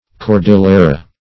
Cordillera \Cor*dil"ler*a\ (k[^o]r*d[i^]l"l[~e]r*[.a]; Sp.